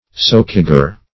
socager - definition of socager - synonyms, pronunciation, spelling from Free Dictionary Search Result for " socager" : The Collaborative International Dictionary of English v.0.48: Socager \Soc"a*ger\, n. (O. Eng. Law) A tennant by socage; a socman.